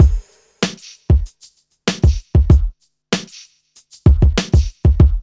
4RB96BEAT2-R.wav